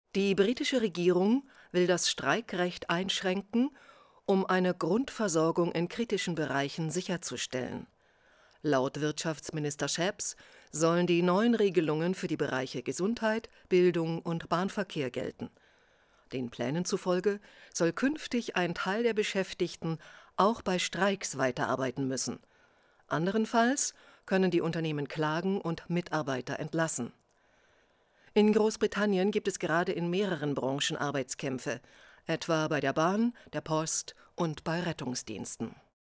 ÖRR Sender
Nachrichten